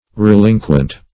Search Result for " relinquent" : The Collaborative International Dictionary of English v.0.48: Relinquent \Re*lin"quent\ (r?-l?n"kwent), a. [L. relinquens, p. pr. of relinqquere.